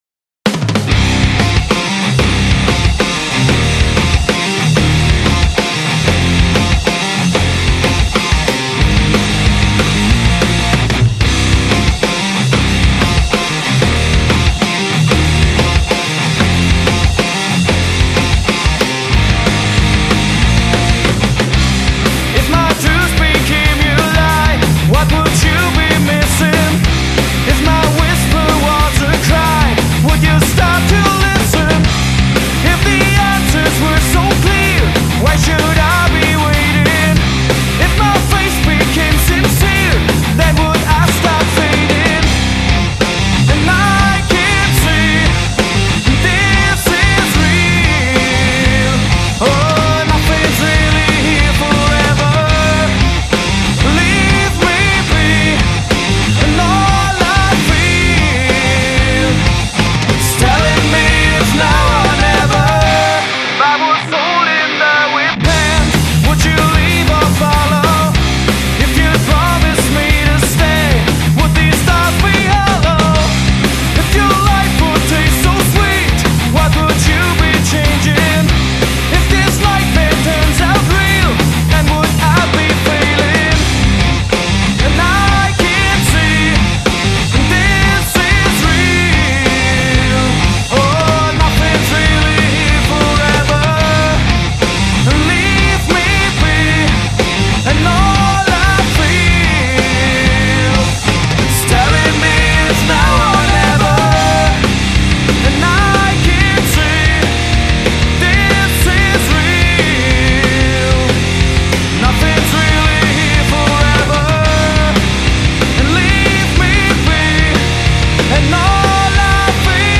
Alternative Rock, Indie-Rock, Post Rock